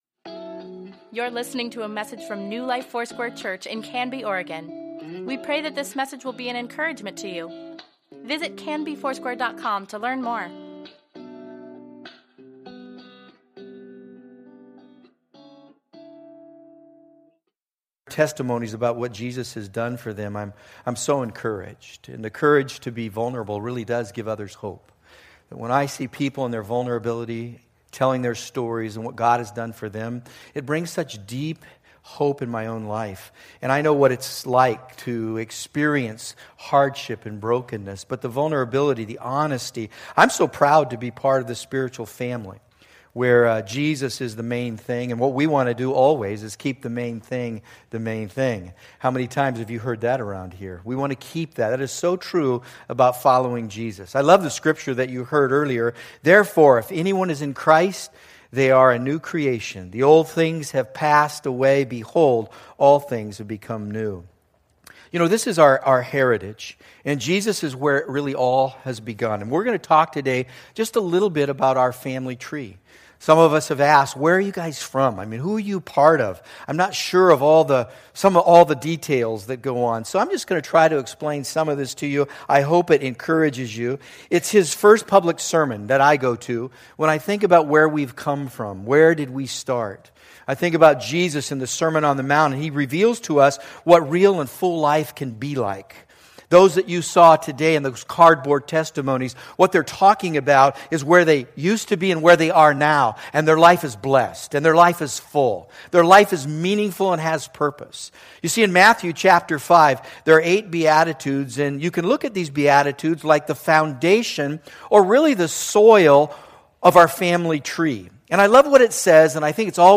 Weekly Email Water Baptism Prayer Events Sermons Give Care for Carus This is Us: The Family Tree September 17, 2017